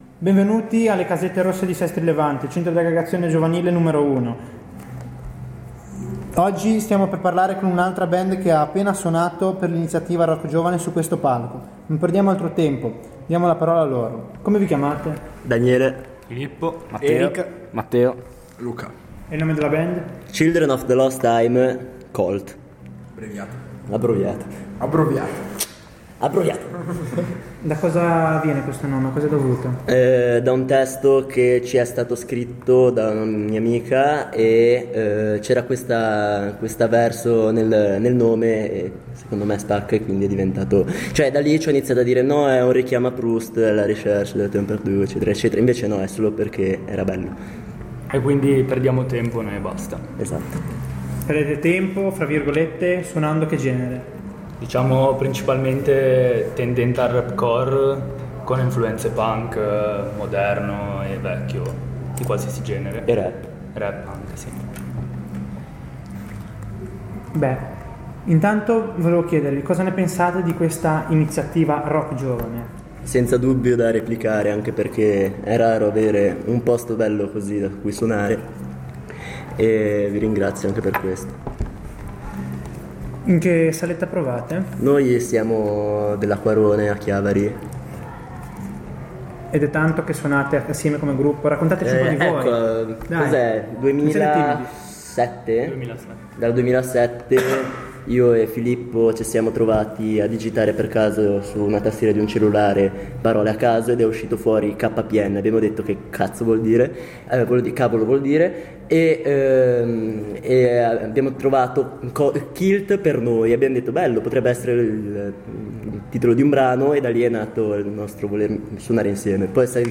In occasione della prima edizione dell'iniziativa itinerante del Tigullio RockGiovane